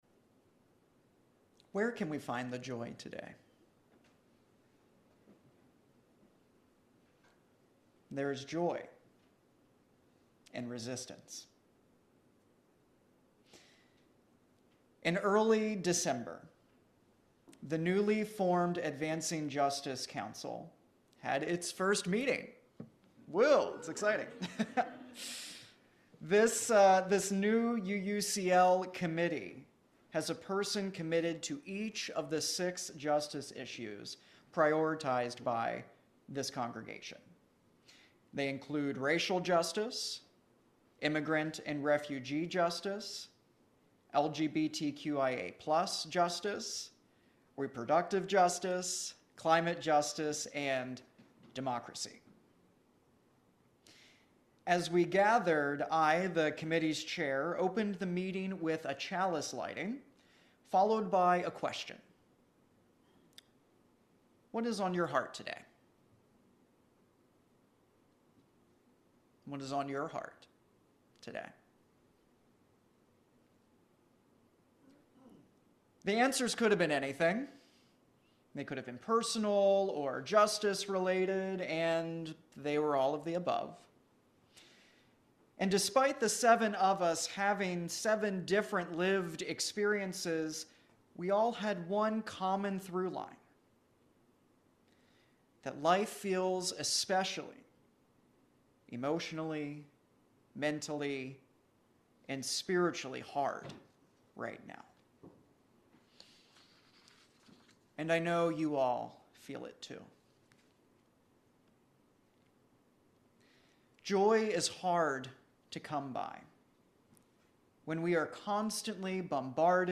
This sermon highlights the Unitarian Universalist commitment to finding joy and community while actively resisting social injustices.